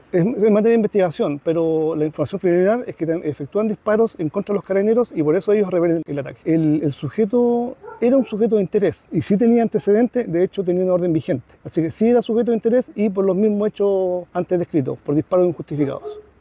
Y es que fue una serie de disparos, al menos 14, los que se efectuaron el miércoles en el sector de Barrio Norte, tal como se observa en el siguiente registro: